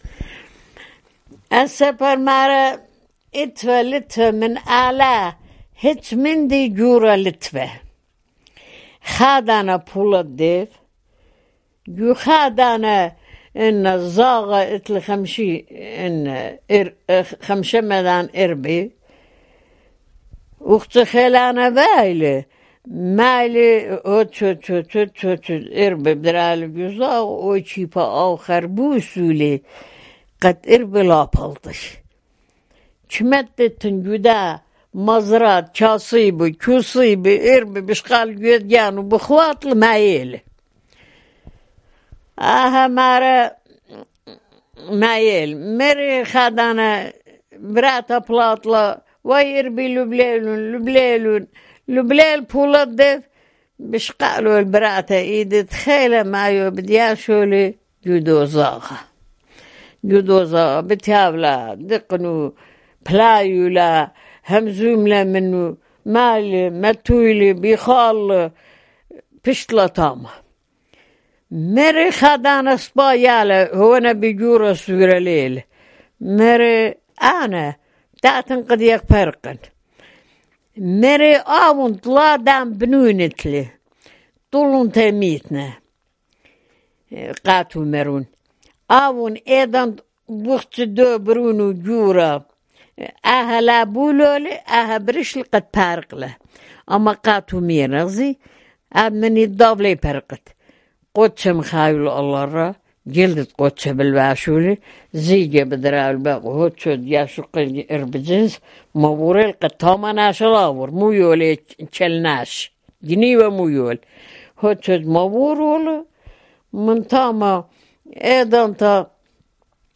Urmi, Christian: The Giant One-Eyed Demon
Arzni, Armenia